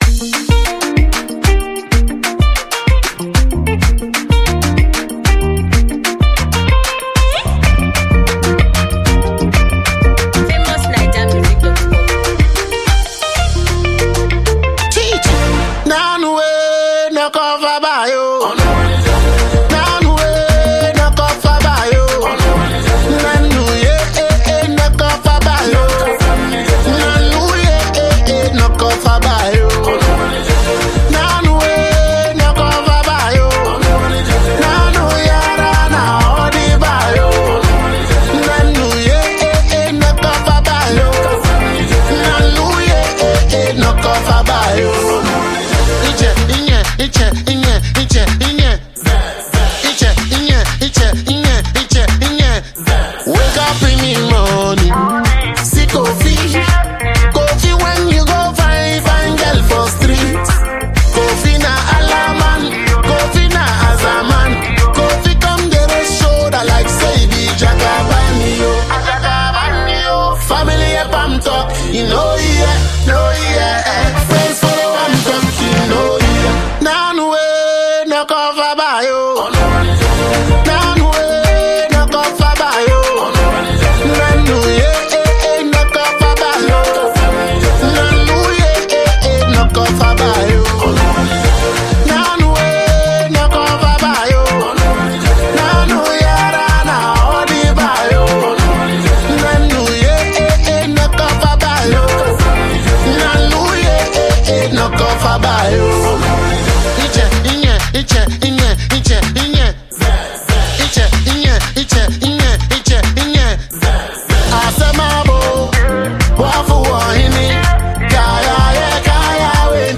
stunning, swarm stimulated melody
Ghanaian reggae-dancehall performer
The snappy tune of this melody won’t ever leave you.